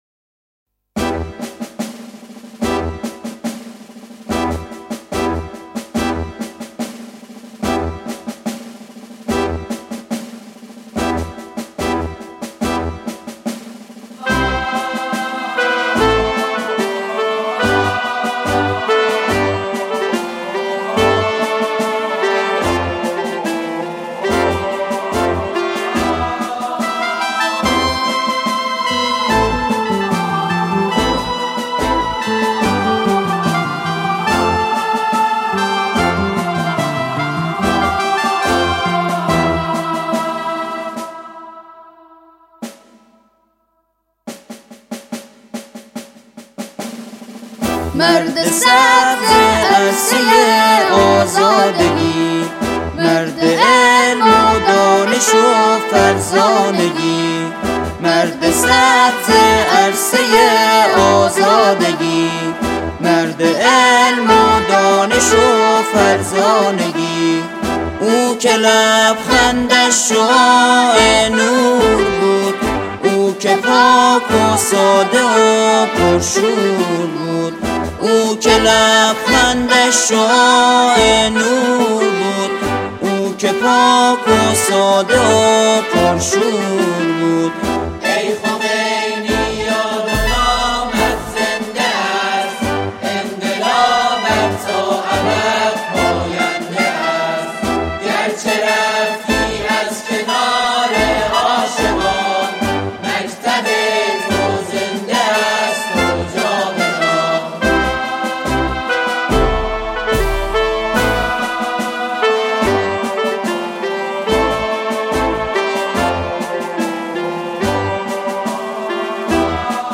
نماهنگ معنوی و حماسی
ژانر: سرود